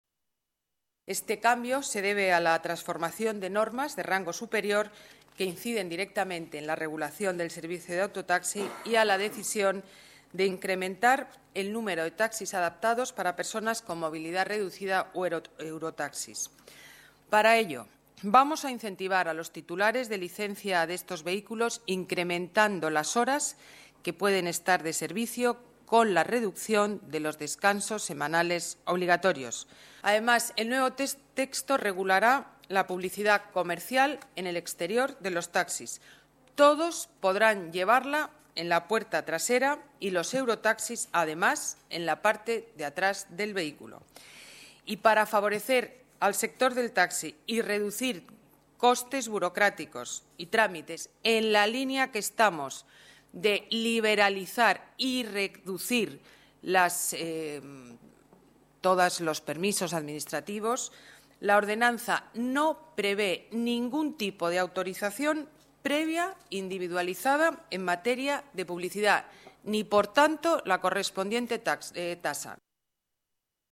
Nueva ventana:Declaraciones alcaldesa de Madrid, Ana Botella: Junta de Gobierno, aprobación inicial nueva Ordenanza Taxi